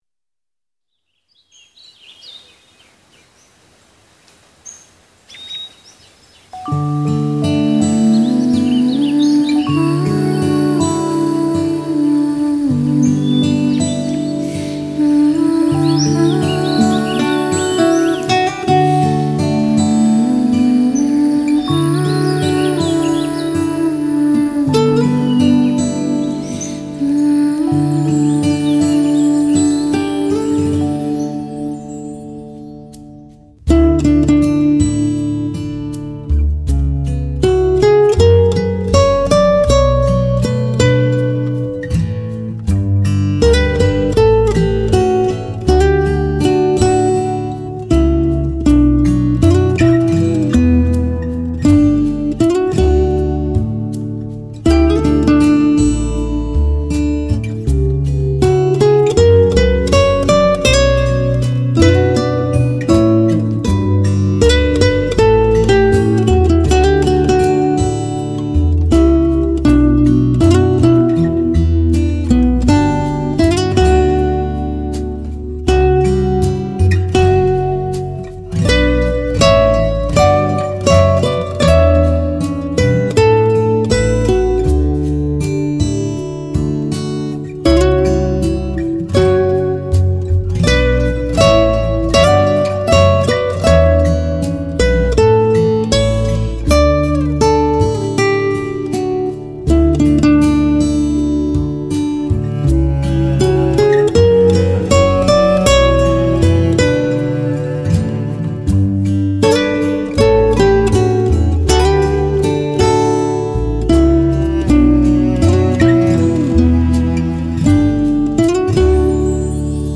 轻轻的拔弦，美婉的情思，荡气回肠打动心弦的演绎，百分百顶级制作。
多声道扩展制作技术震撼登场，采用美国最新核心专利技术独立七声道扩展环绕声系统制作。